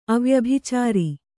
♪ avyabhicāri